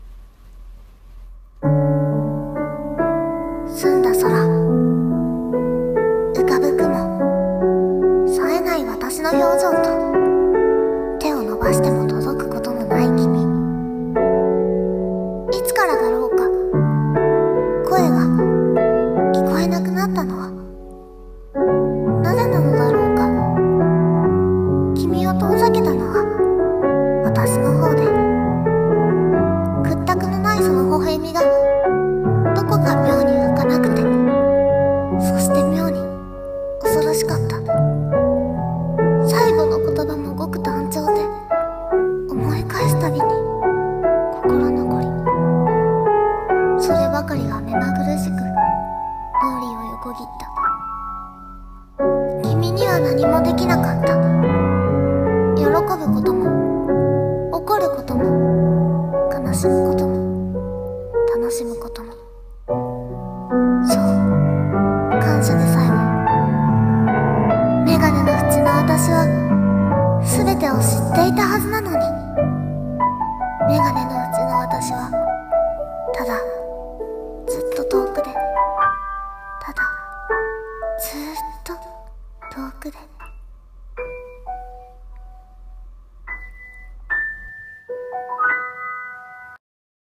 朗読台本「めがねのふち」声劇コラボ用